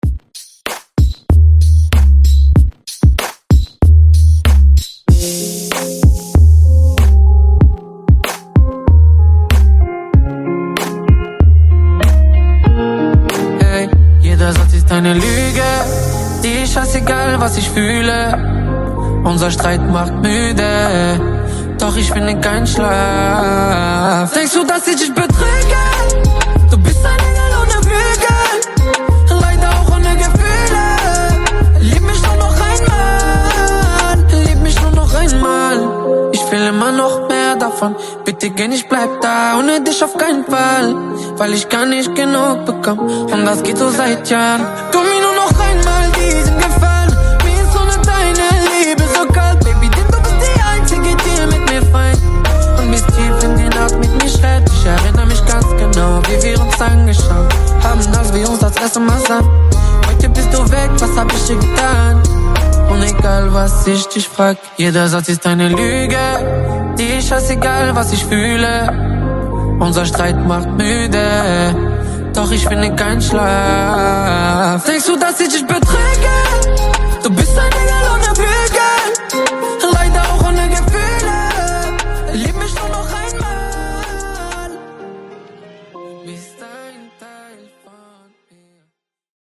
Genre: 70's
Clean BPM: 129 Time